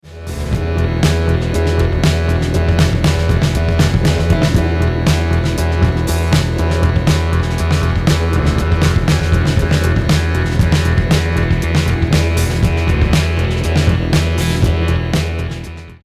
Clip 5 (5:35) – Drums break loose, song starts to power down